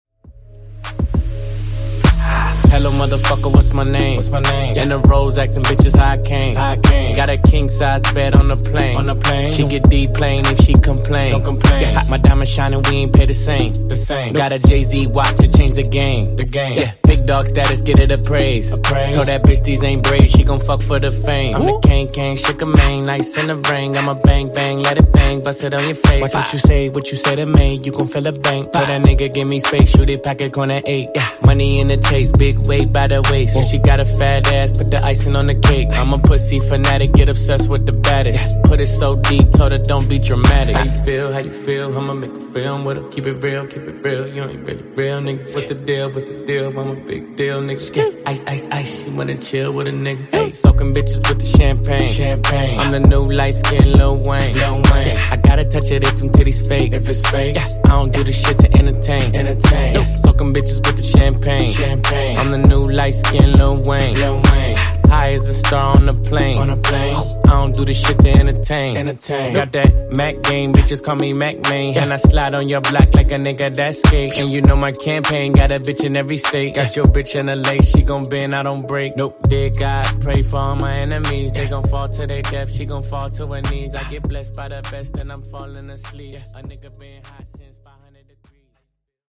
Genre: RE-DRUM
Clean BPM: 102 Time